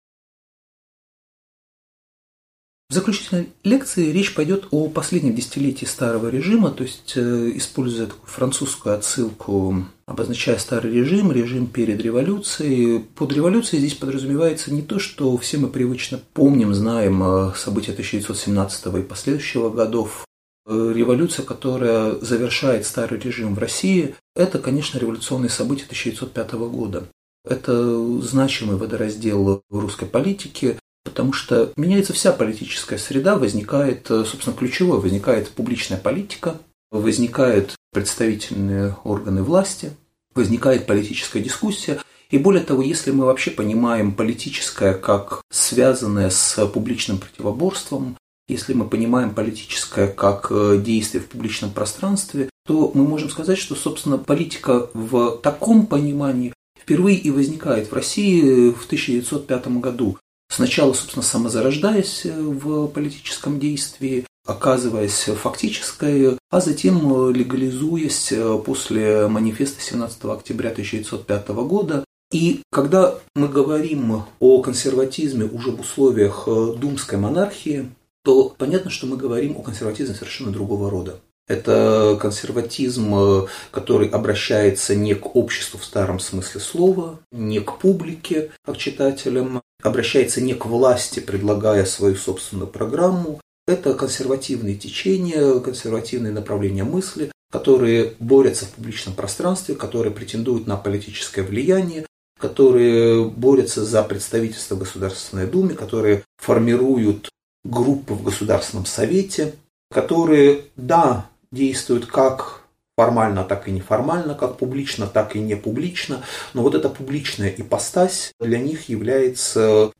Аудиокнига Лекция «Последнее десятилетие «старого режима»» | Библиотека аудиокниг